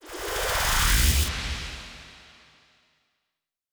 VTS1 Incast Kit Sound FX
VTS1 Incast Kit 140BPM ReverseFx2.wav